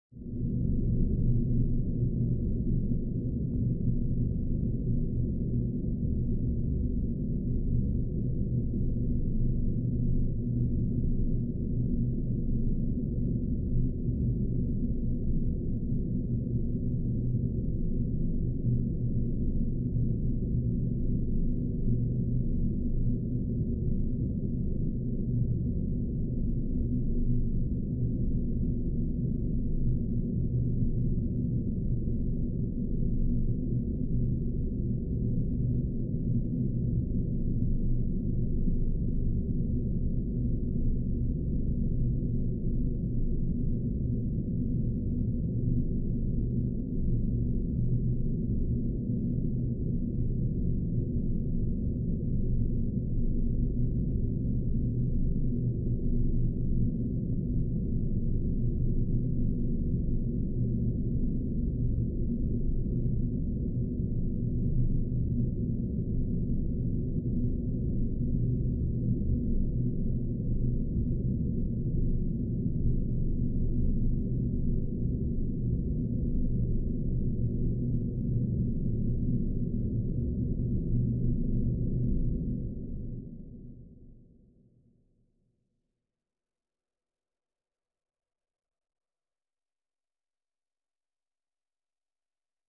dark_house.ogg